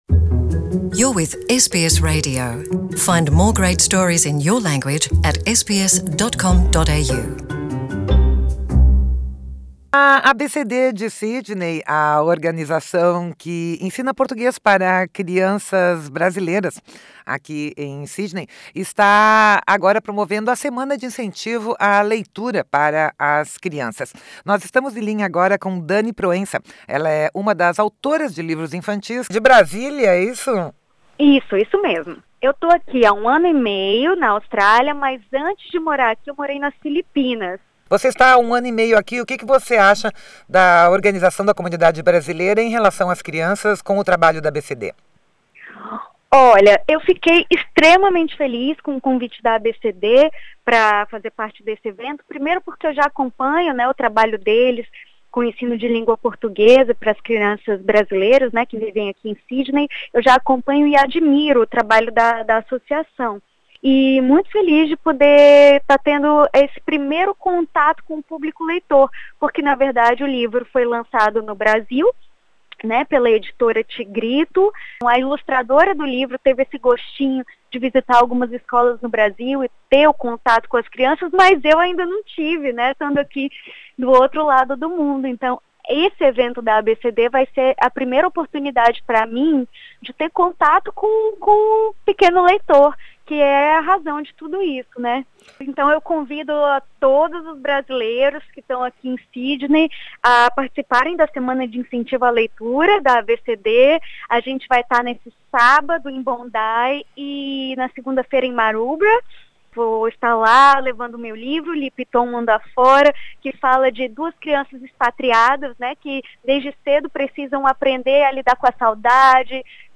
Ouça aqui o podcast com a entrevista.